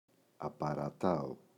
απαρατάω [apara’tao]